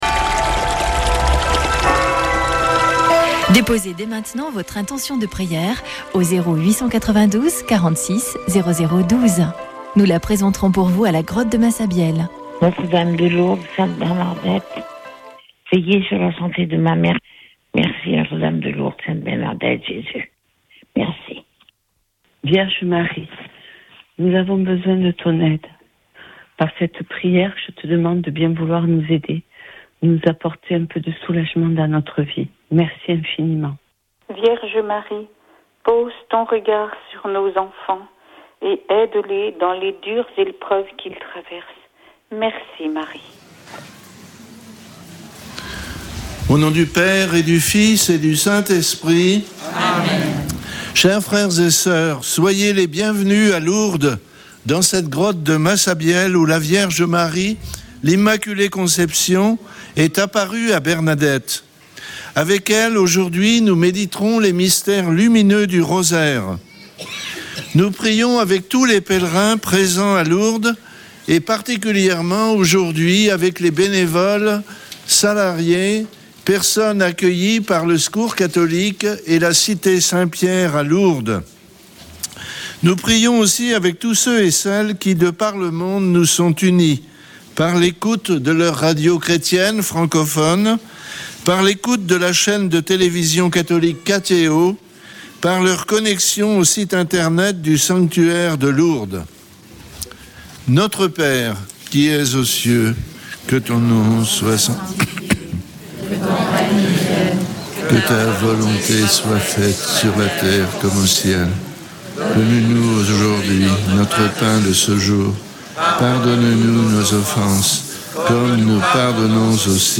Une émission présentée par Chapelains de Lourdes